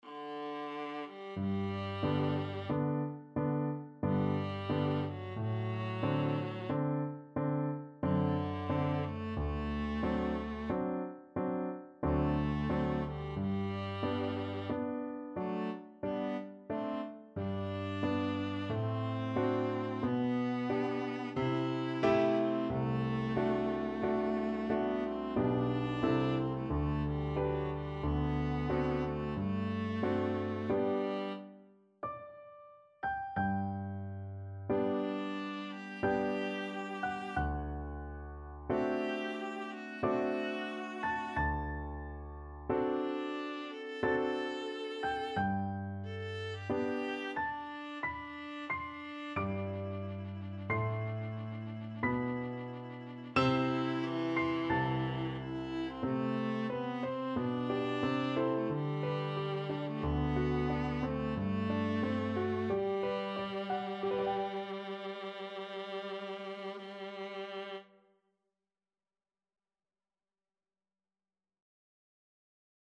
Viola
3/4 (View more 3/4 Music)
G major (Sounding Pitch) (View more G major Music for Viola )
Adagio =45
beethoven_trio-in-b-flat-major_2nd_VLA.mp3